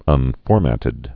(ŭn-fôrmătĭd)